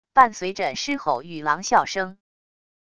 伴随着狮吼与狼啸声wav音频